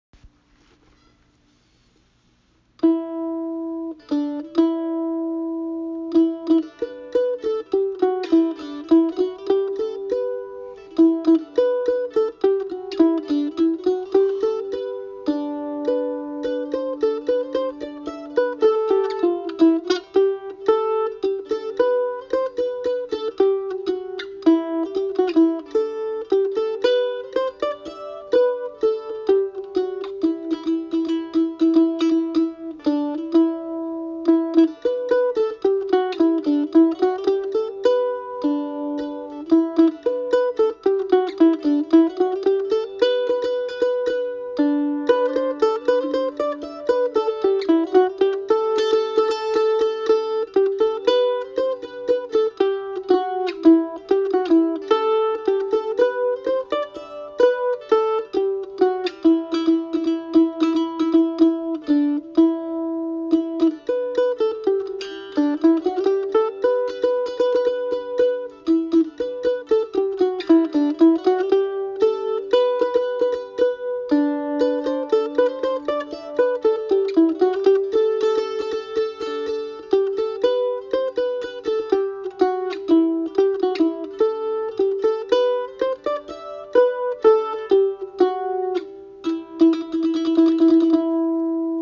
Played on The Loar mandolin
RecordingMerry-Gentleman-loar-mandolin-.mp3